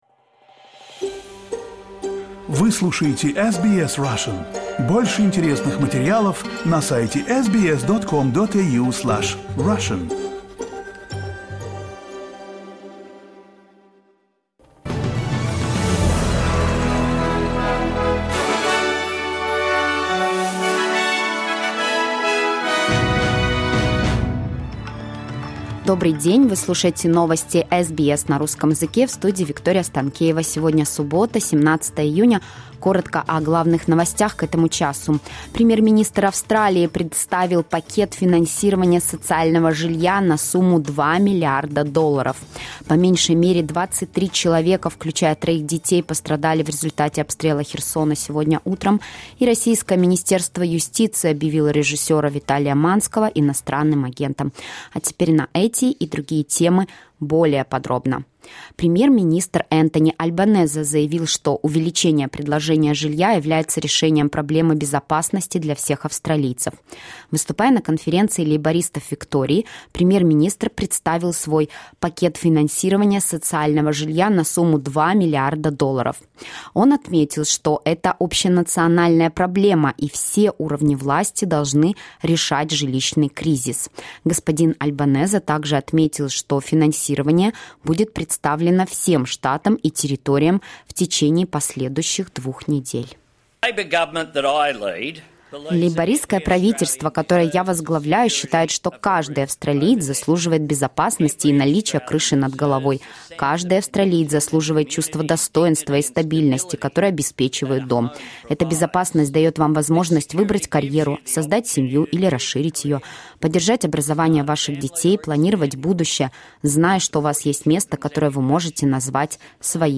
SBS news in Russian — 17.06.2023